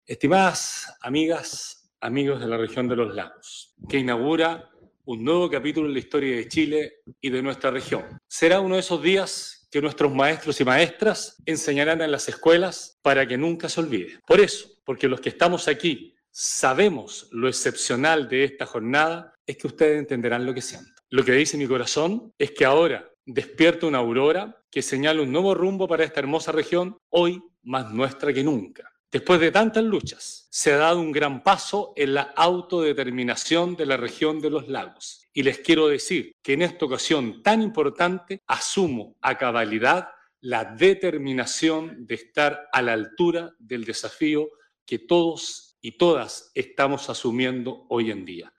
Este miércoles 14 de julio, al medio día, en dependencias del salón azul del Gobierno Regional de Los Lagos, se realizó la ceremonia de asunción del nuevo Gobernador de la Región de Los Lagos, Patricio Vallespín López, la que contó con la presencia de parlamentarios, consejeros regionales, alcaldes, entre otras autoridades de la región y el ex Intendente, ahora, Delegado Presidencial Regional, Carlos Geisse.
Por su parte, el recién asumido Gobernador, Patricio Vallespín, indicó: